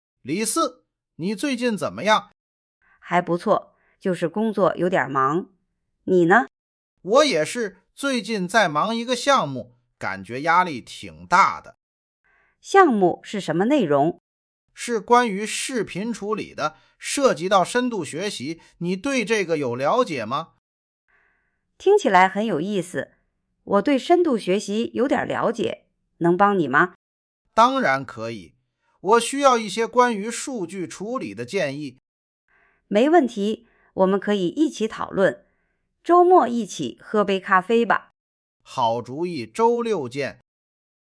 允许设置两个角色进行对话，方便创作对话类内容。
5. 双角色对话生成
【生成结果】